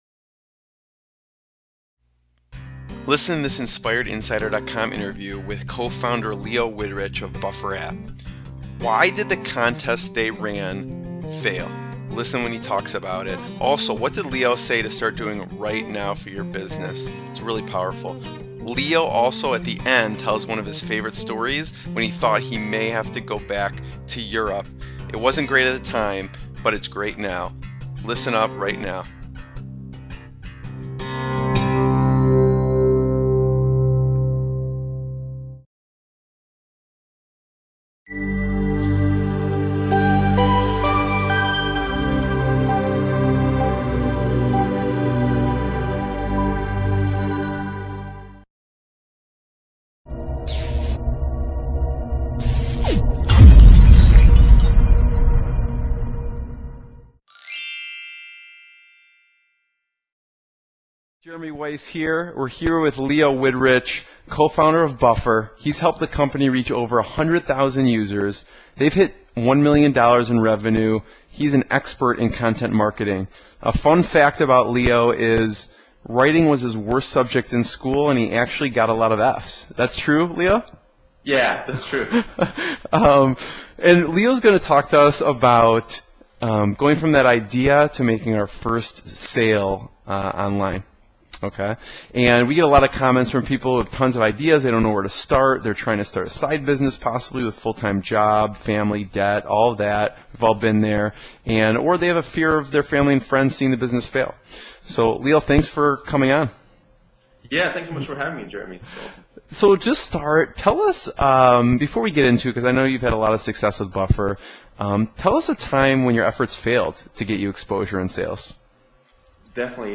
Watch this interview to see: [Video & mp3 included] -What was a time that all their efforts failed? -How did they get their first sale? -When did one investor know they were mainstream?